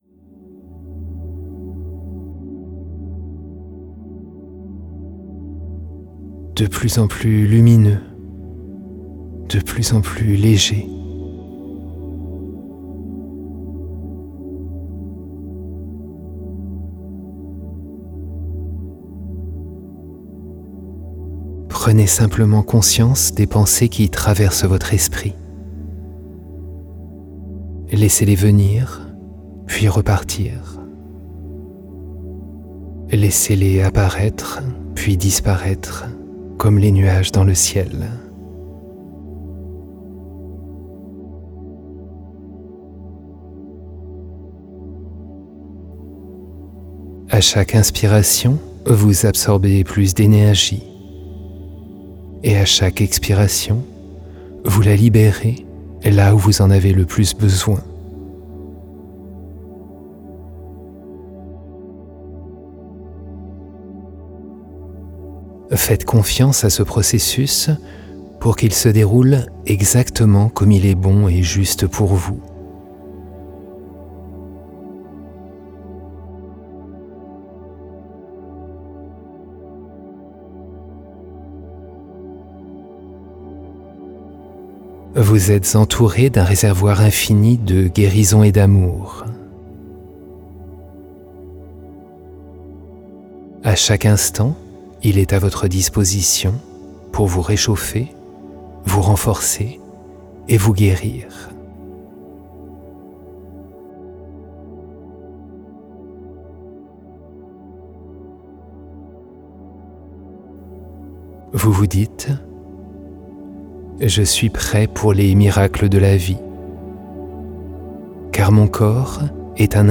Relaxation profonde guidée pour activer les forces d'auto-guérison